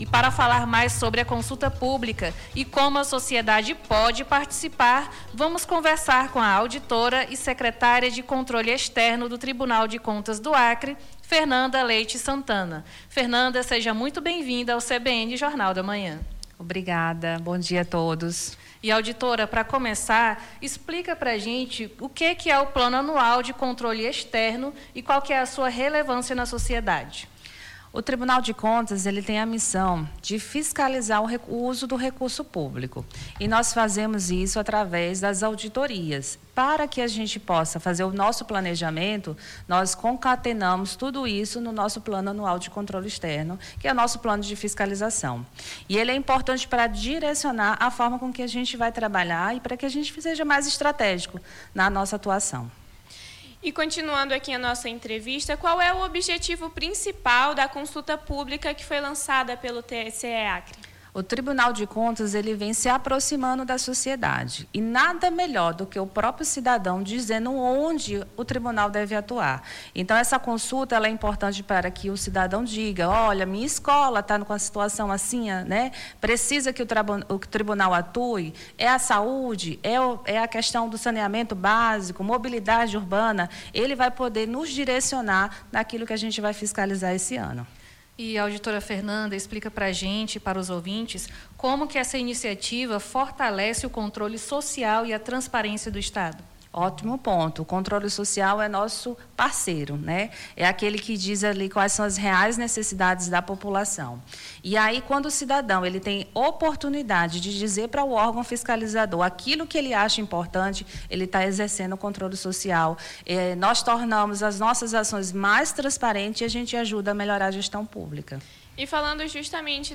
Nome do Artista - CENSURA - ENTREVISTA CONSULTA PUBLICA TCE - 24-03-26.mp3